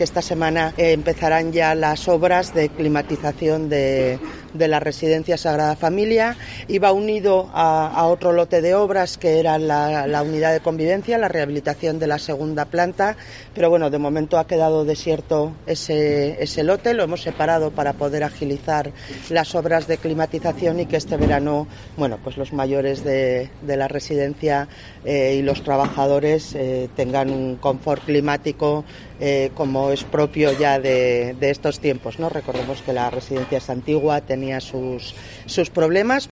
Carmen Susín, Consejera de Bienestar Social